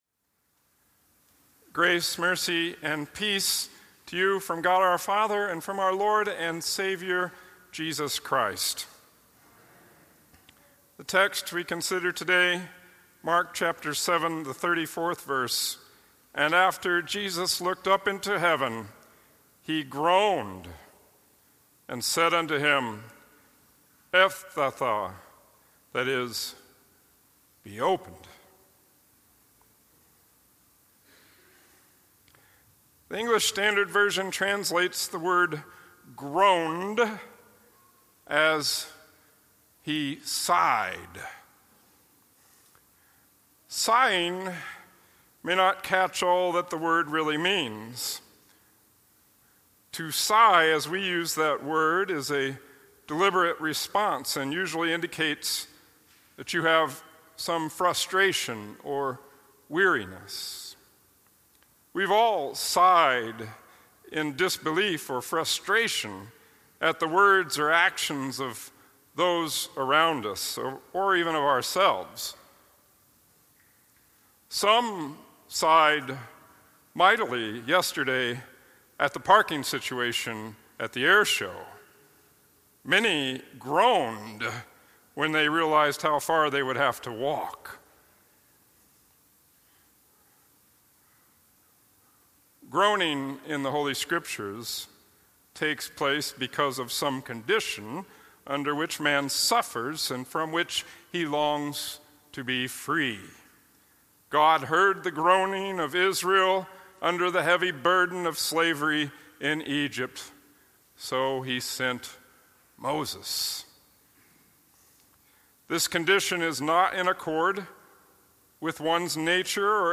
Twelfth Sunday after Trinity